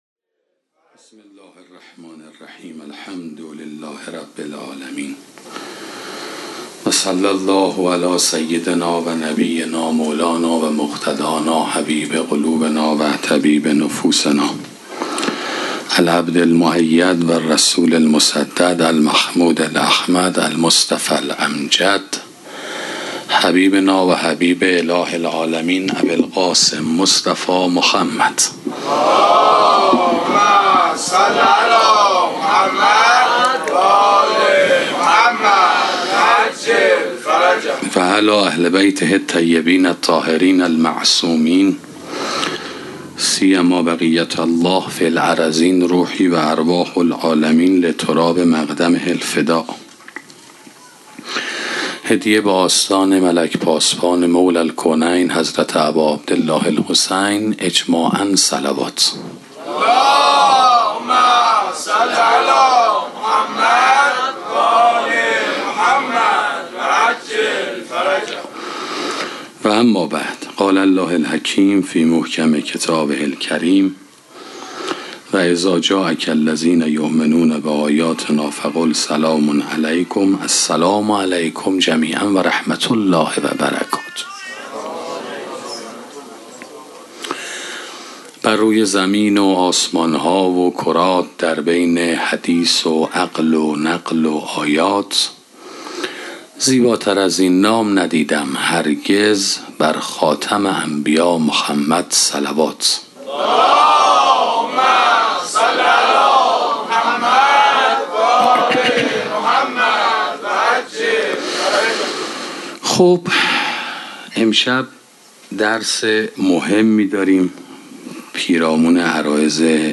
سخنرانی جایگاه معنوی مادر 3